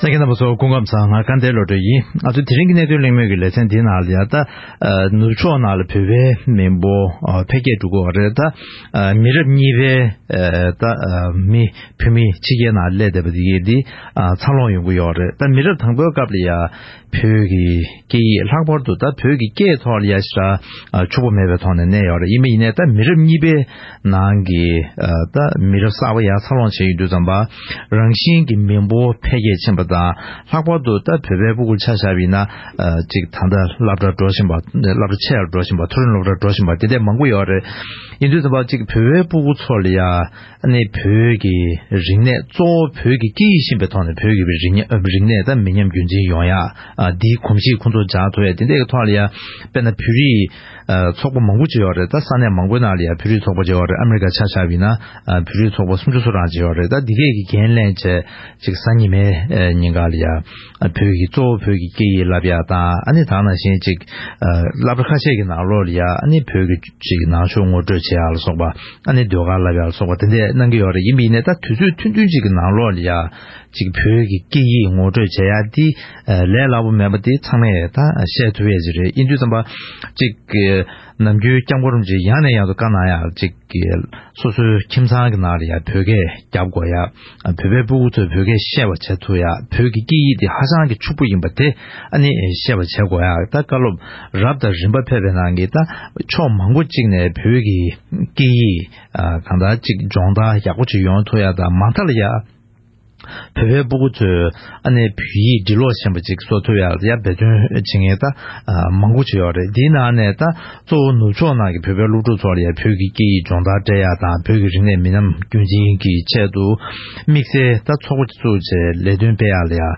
༄༅། །ཐེངས་འདིའི་གནད་དོན་གླེང་མོལ་གྱི་ལེ་ཚན་ནང་།